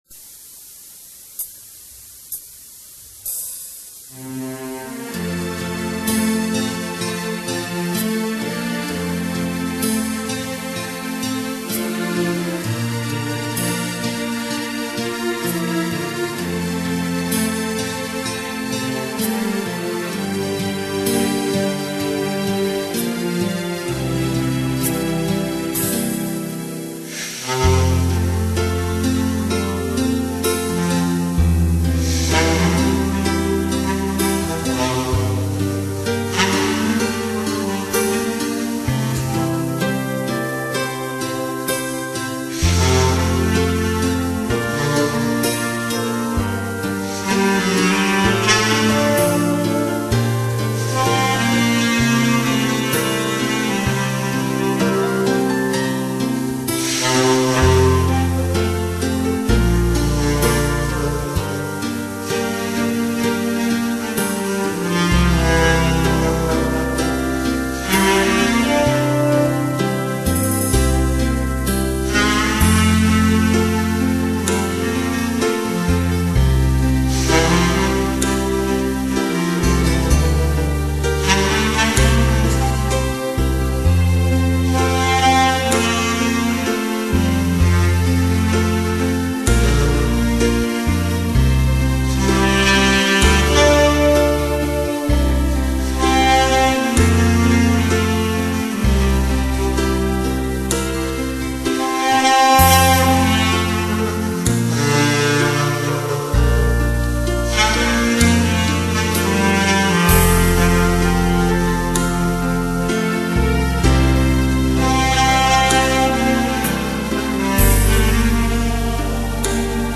색소폰